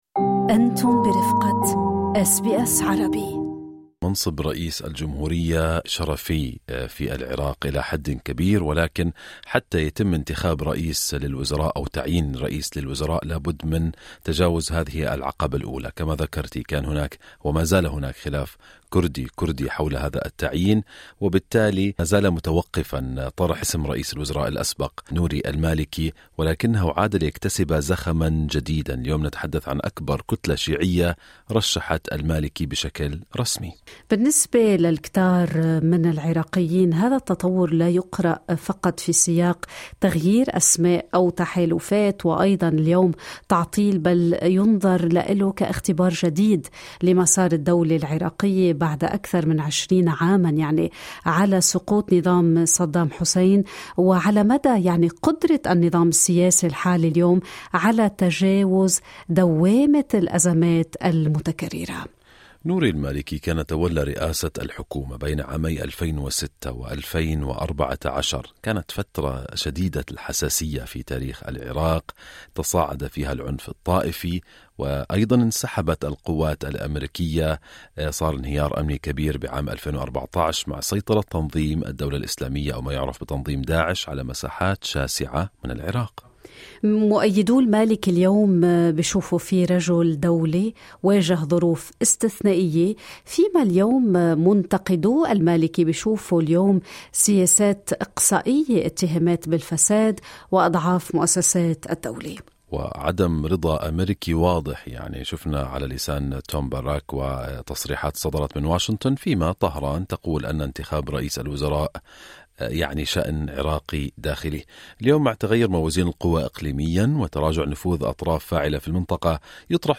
في لقاء ضمن برنامج صباح الخير أستراليا